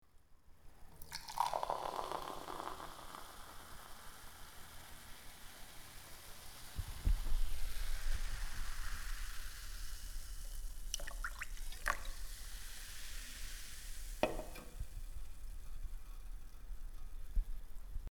Наливание Колы в стакан, пузырьки лопаются, шипение напитка